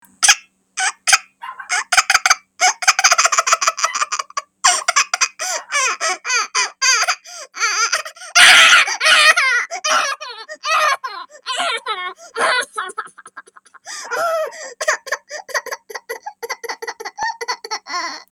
Baby Crying 14 Sound Effect Download: Instant Soundboard Button